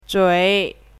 chinese-voice - 汉字语音库
zhui3.mp3